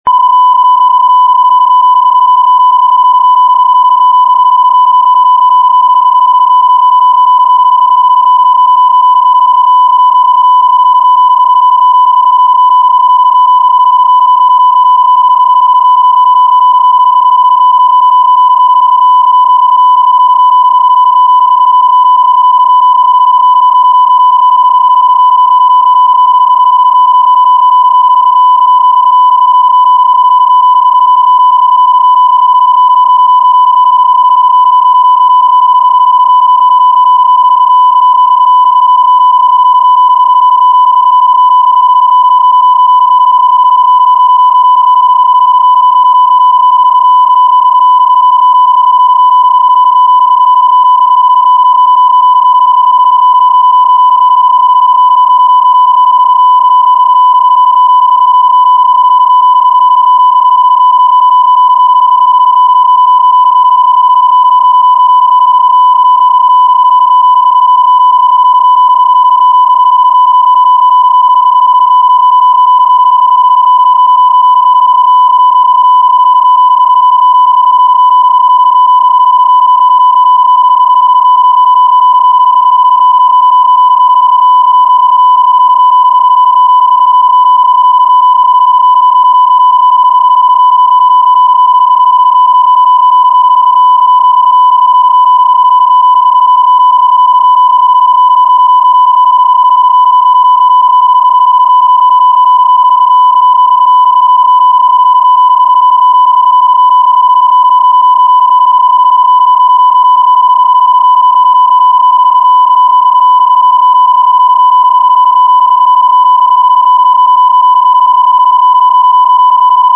PSK31
• DBPSK Modulation
• Geringe Bandbreite (< 50 Hz)
psk31cq.mp3